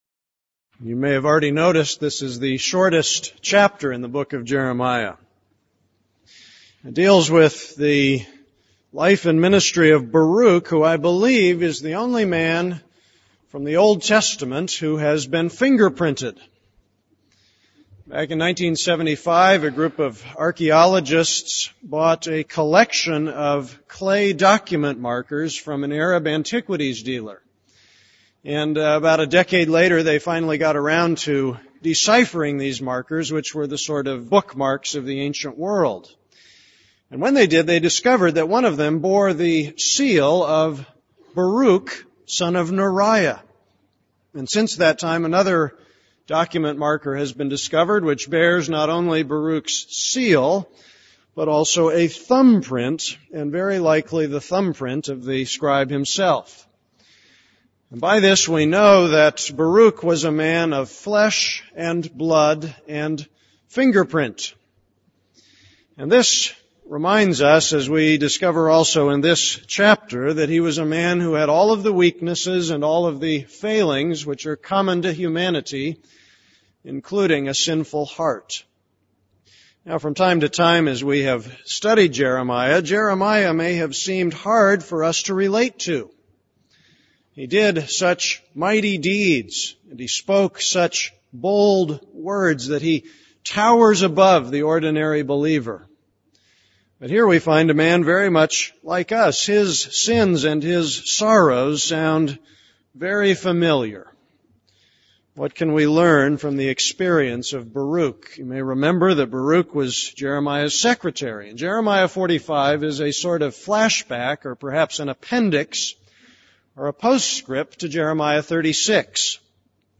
This is a sermon on Jeremiah 45:1-5.